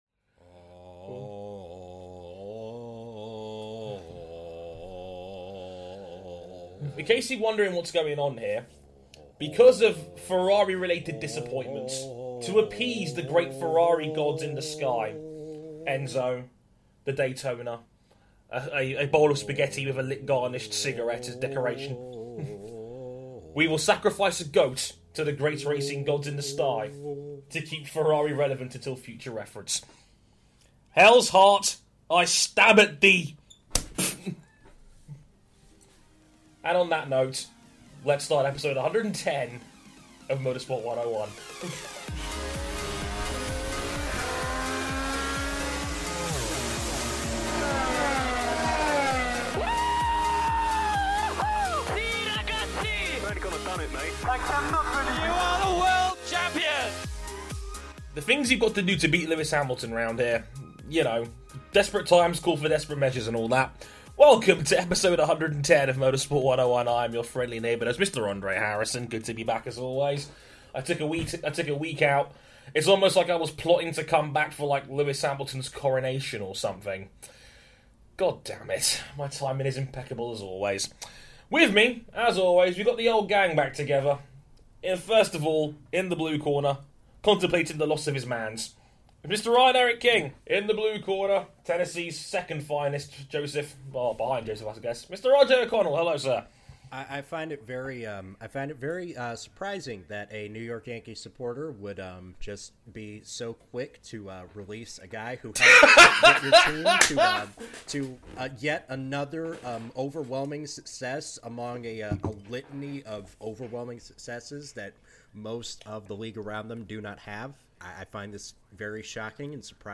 Note: The Keepin' It 101 from this week's show sadly got corrupted.